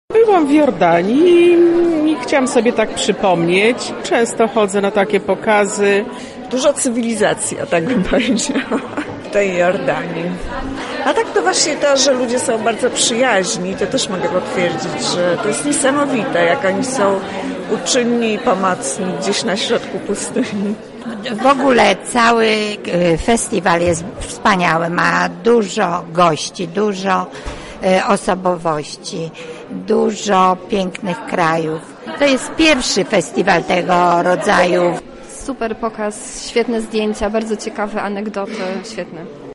Nasz reporter wziął udział w pogoni za jednorożcem podczas spotkania poświęconemu Jordanii.